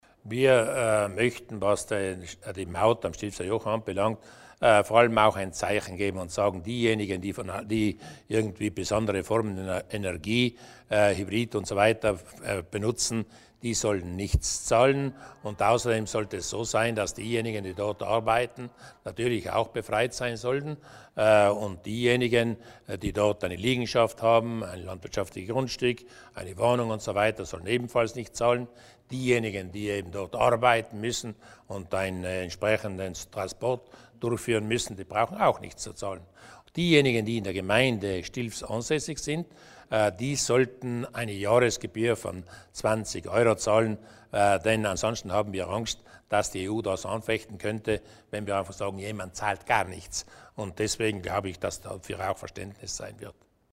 Landeshauptmann Luis Durnwalder zu den Neuigkeiten auf der Stilfserjoch-Straße